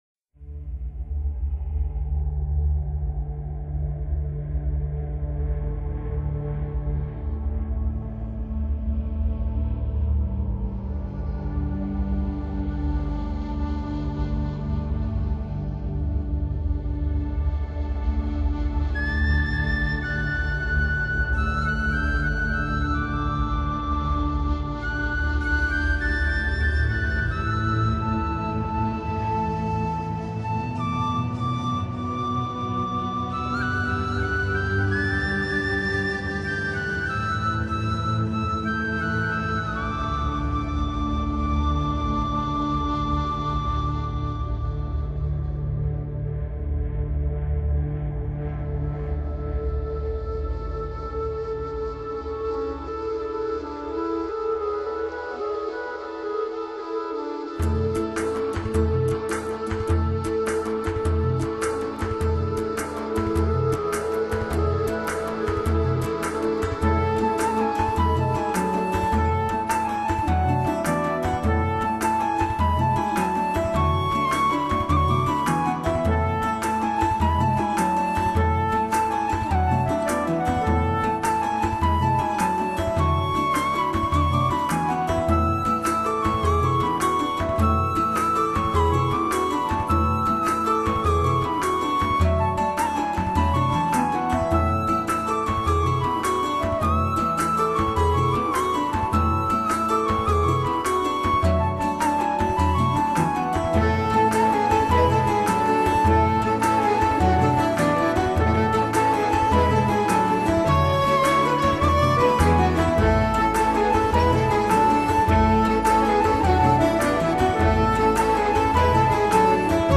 Genre:Celtic / Folk / Soundtracks